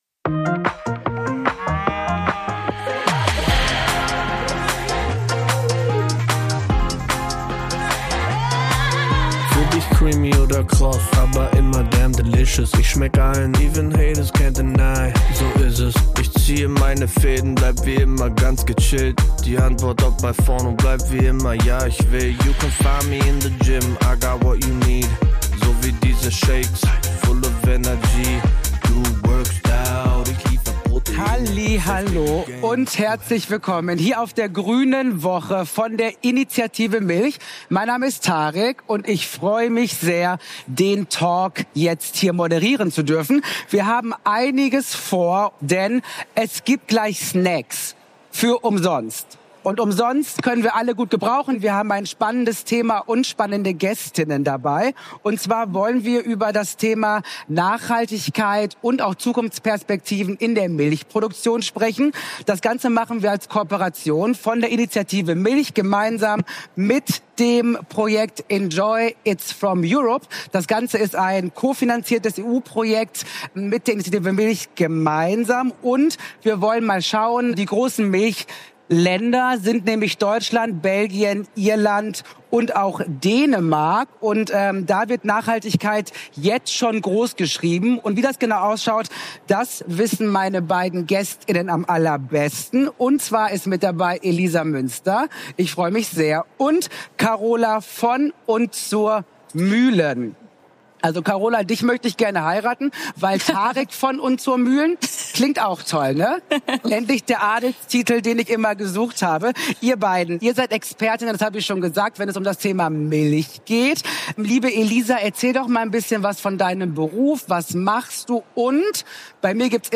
Auf der diesjährigen Grünen Woche in Berlin haben wir mit unseren Talkgästen über die Erfolgsstory „Milch made in Europa“ gesprochen.